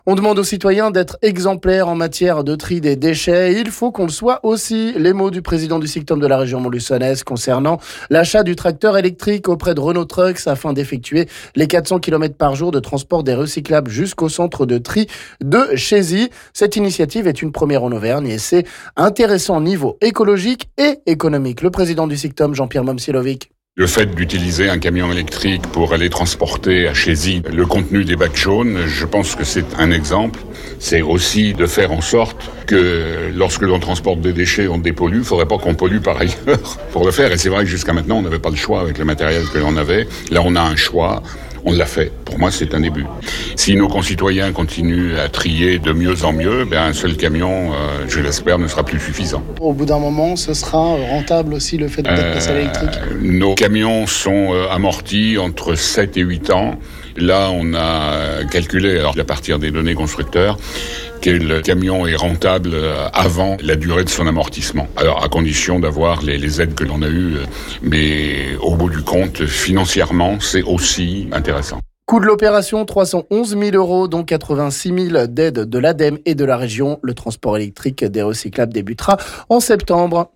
Le président du SICTOM Jean-Pierre Momcilovic nous en dit plus...